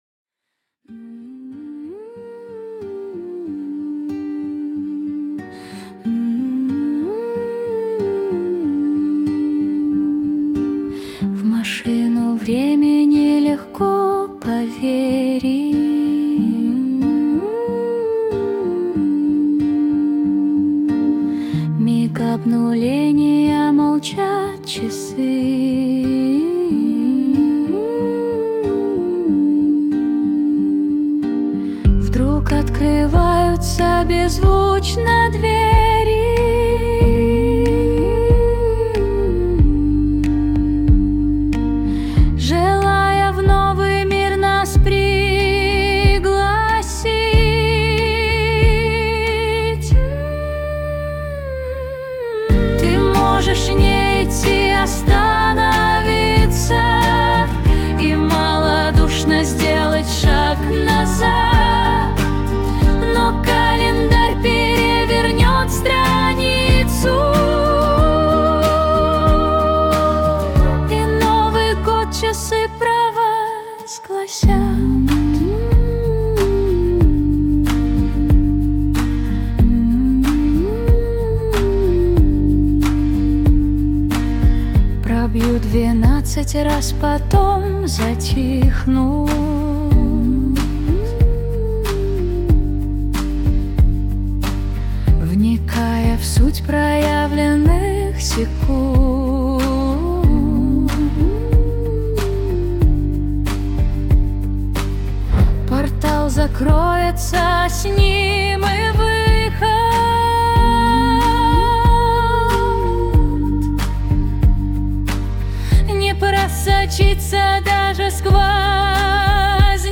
mp3,3780k] Авторская песня